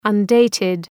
Shkrimi fonetik {ʌn’deıtıd}